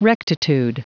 Prononciation du mot rectitude en anglais (fichier audio)
Prononciation du mot : rectitude